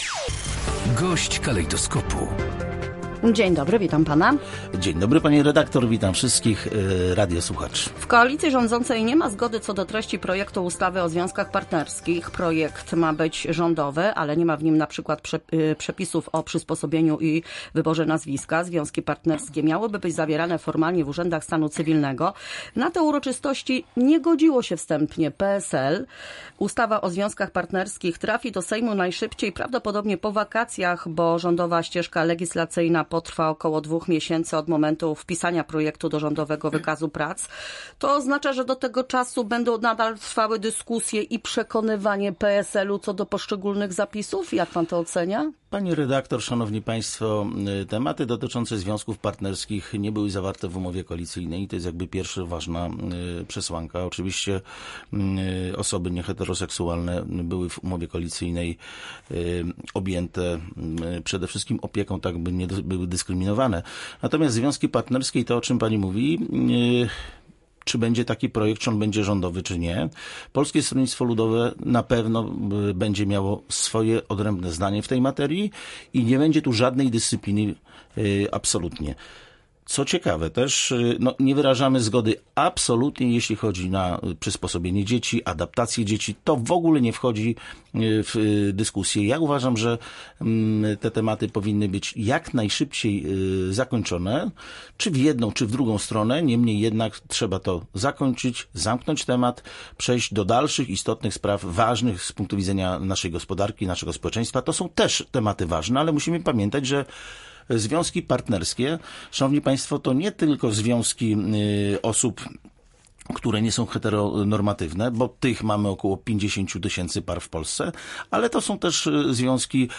-Tematy dotyczące związków partnerskich nie były zawarte w umowie koalicyjnej – powiedział na naszej antenie poseł Trzeciej Drogi (PSL) Adam Dziedzic.